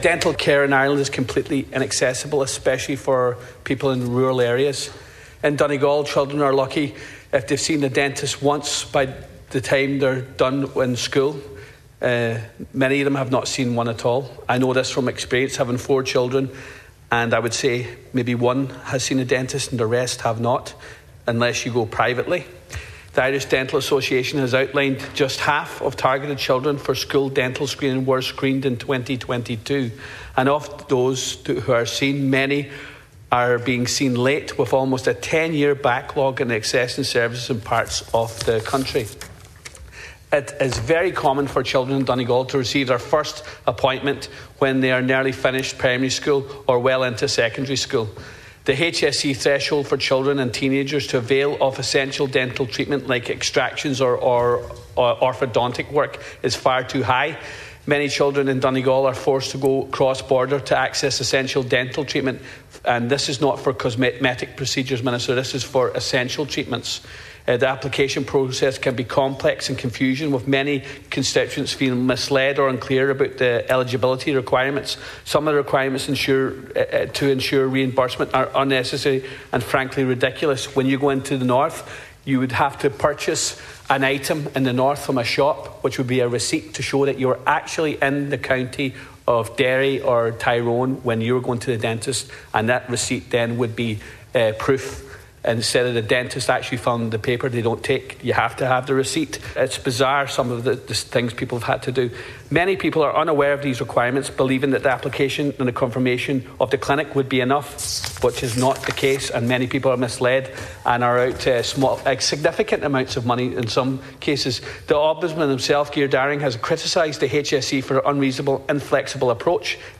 The 100% Redress TD told the Dail today he doesn’t believe issues in the public or private dental sector, particularly in rural areas, can be addressed without the establishment of a new dental school.
You can hear Deputy Ward’s full contribution here –